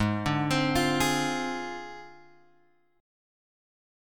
G# 6th Flat 5th